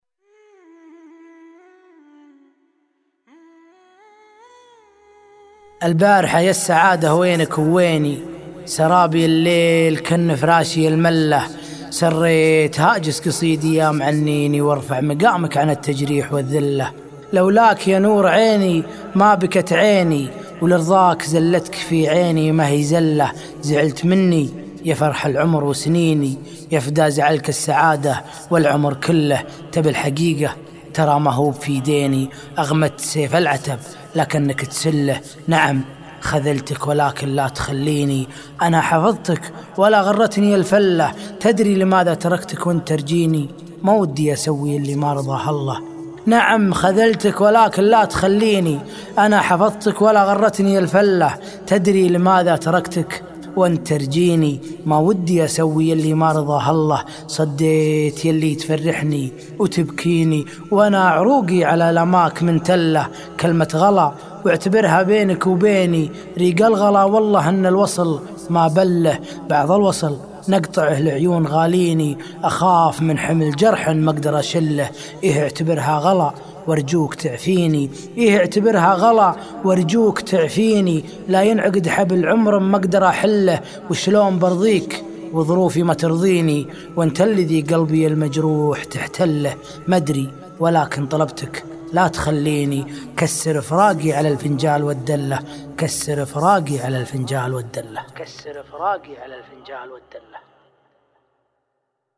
شيلات